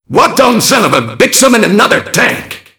mvm_tank_alerts03.mp3